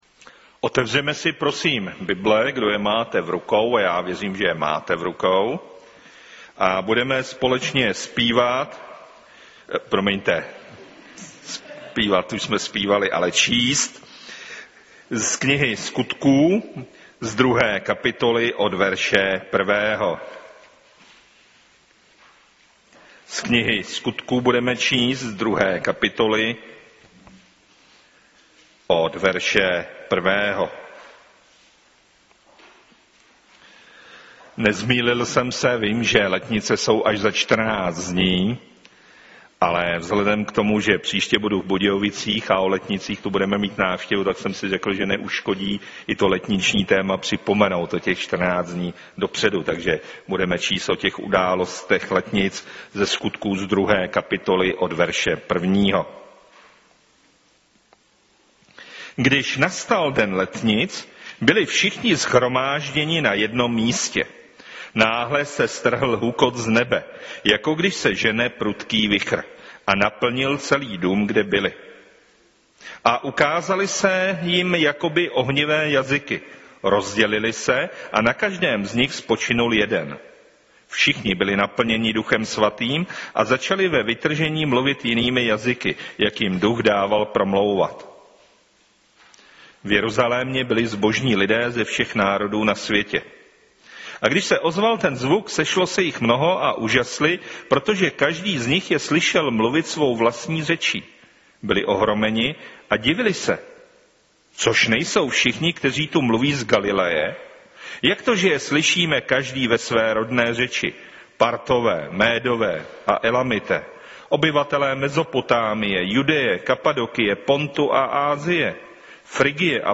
Kategorie: Nedělní bohoslužby Husinec